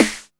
GRITTY.wav